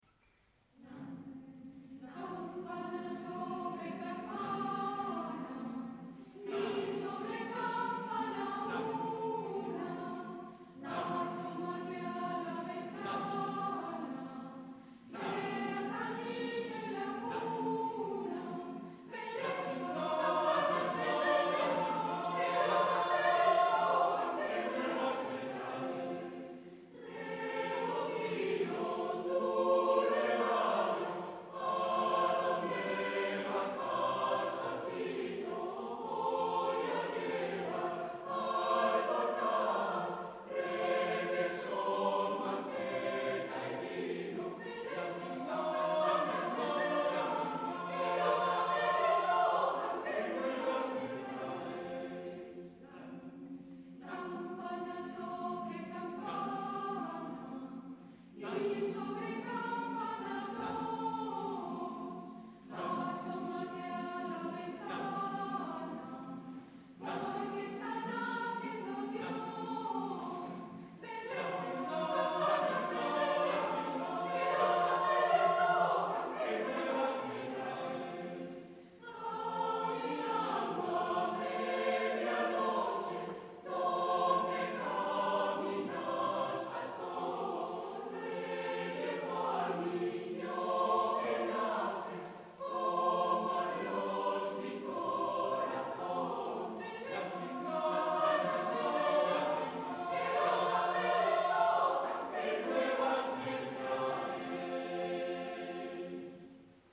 en concierto